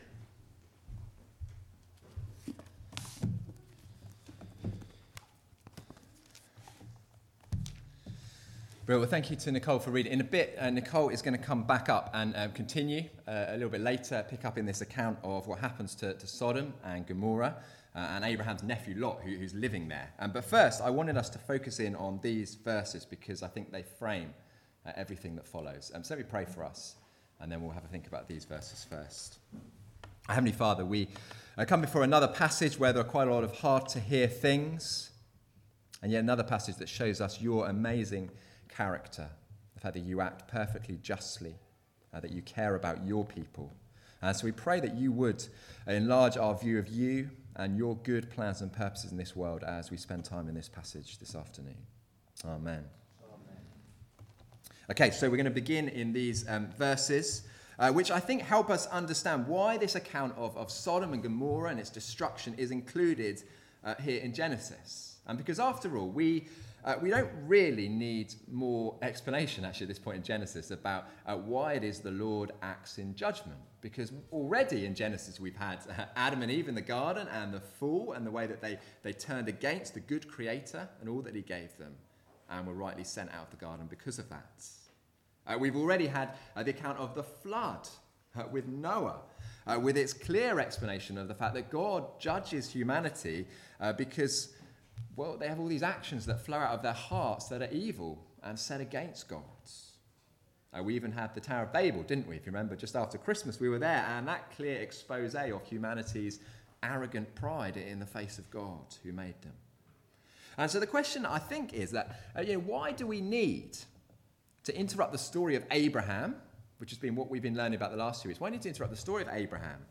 The Life and Times of Abraham Passage: Genesis 18:16-33 Service Type: Weekly Service at 4pm « How the Church Grows Using the “Not very useful” »